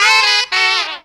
3 NOTE RIFF.wav